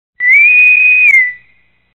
Star Trek Intercom Whistle